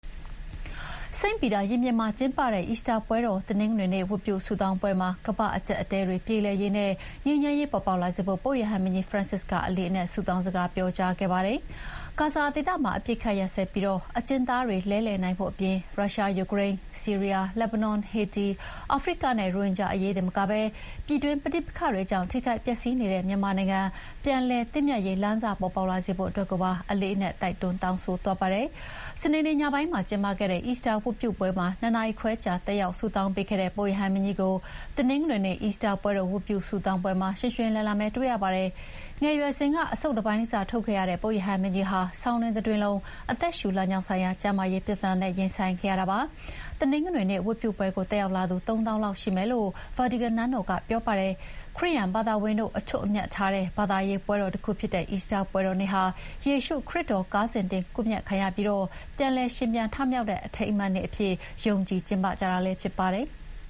စိန့်ပီတာရင်ပြင်မှာကျင်းပတဲ့ အီစတာပွဲတော် တနင်္ဂနွေနေ့ ဝတ်ပြုဆုတောင်းပွဲမှာ ကမ္ဘာ့အကျပ်အတည်းတွေပြေလည်ရေးနဲ့ ငြိမ်းချမ်းရေးပေါ်ပေါက်လာစေဖို့ ပုပ်ရဟန်းမင်းကြီး Francis က အလေးအနက် ဆုတောင်းစကား ပြောကြားခဲ့ပါတယ်။